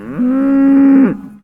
cow.ogg